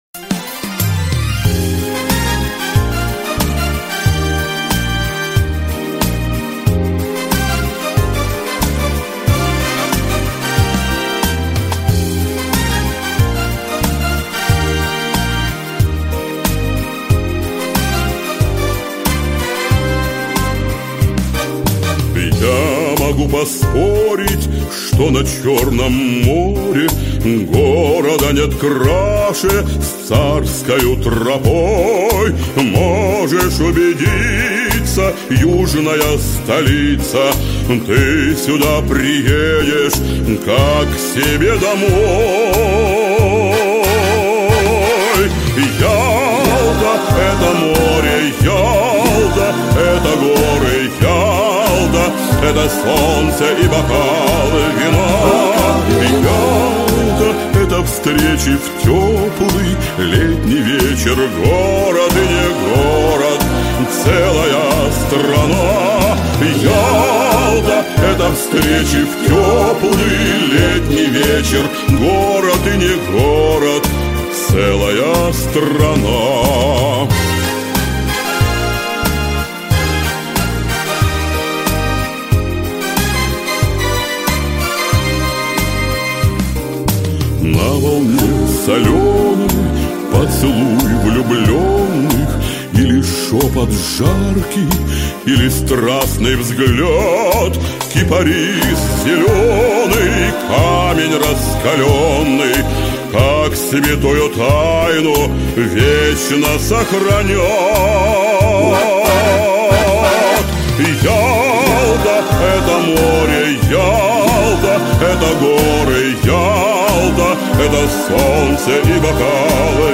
официальную торжественную композицию